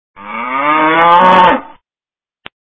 /32kbps) 16kbps (5.1кб) Описание: Мычание коровы ID 24935 Просмотрен 7167 раз Скачан 2047 раз Скопируй ссылку и скачай Fget-ом в течение 1-2 дней!